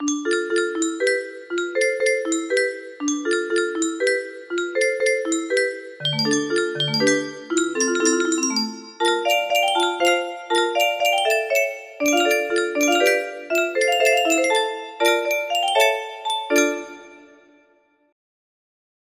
Full range 60
Fixed chords in the middle part.